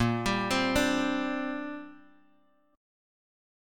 A#6add9 Chord
Listen to A#6add9 strummed